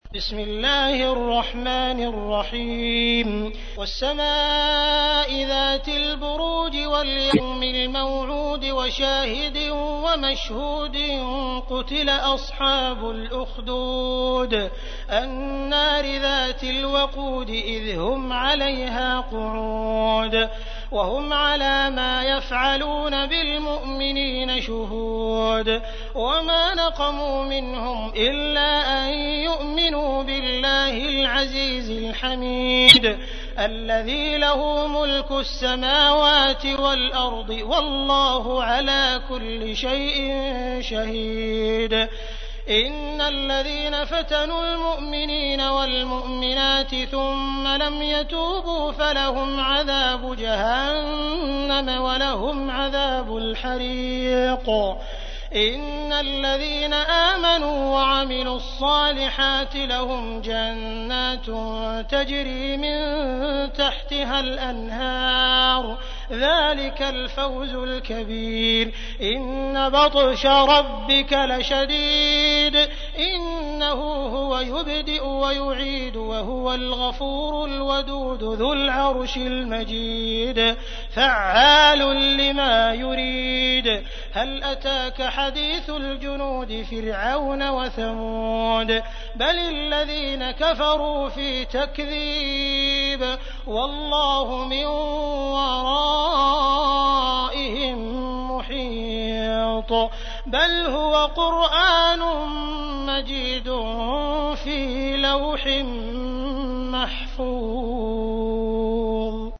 تحميل : 85. سورة البروج / القارئ عبد الرحمن السديس / القرآن الكريم / موقع يا حسين